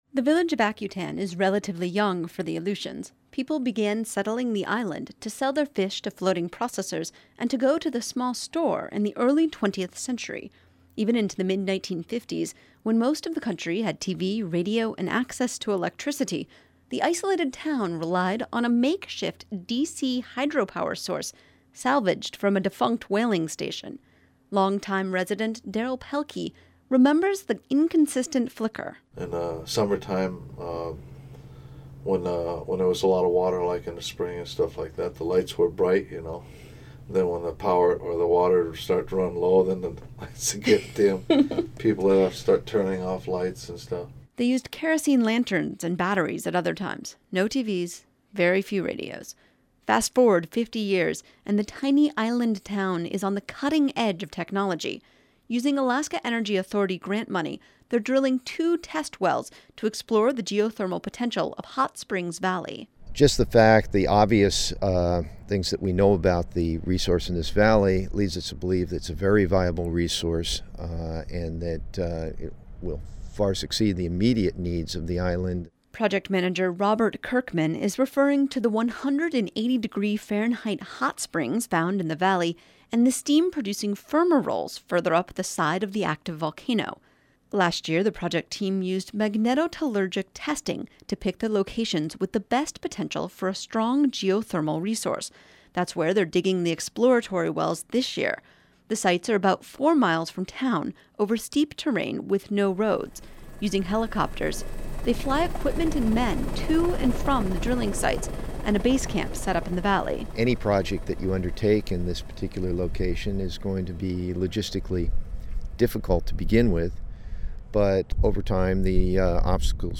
visited Hot Springs Bay on Akutan Island